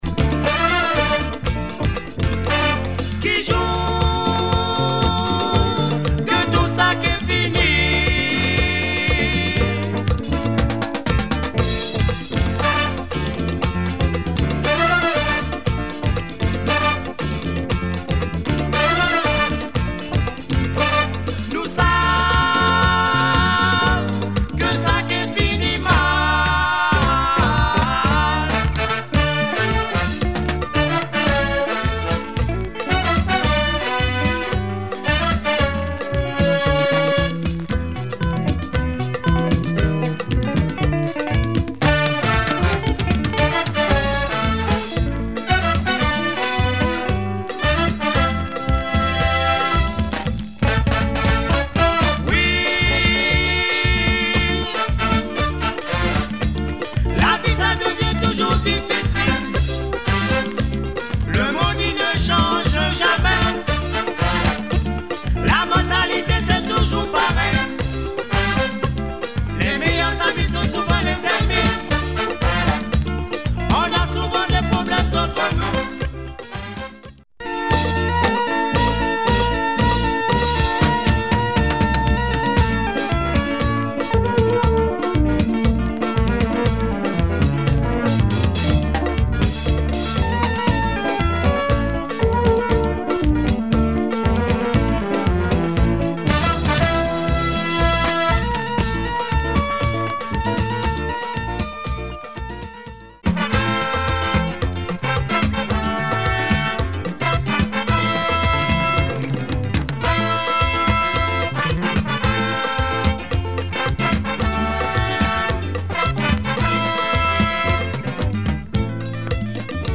Very groovy biguine album